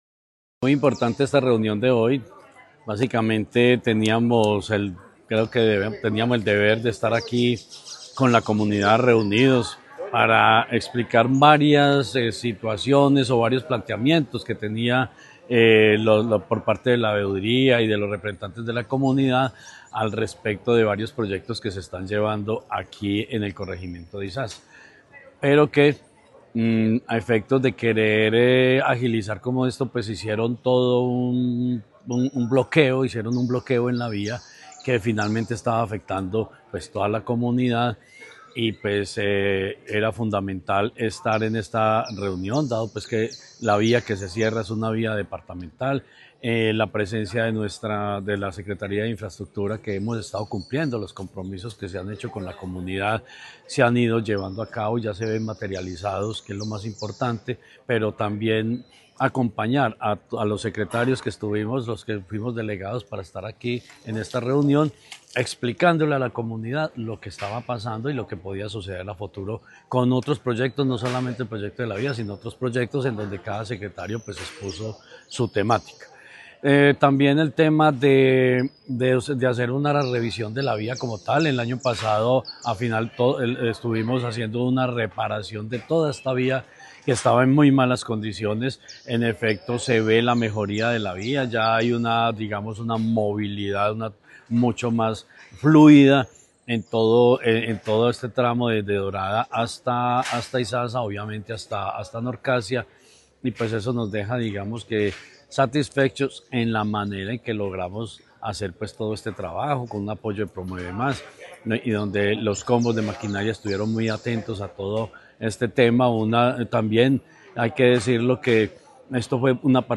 Jorge Ricardo Gutiérrez Cardona, secretario de Infraestructura.